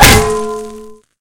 bang.ogg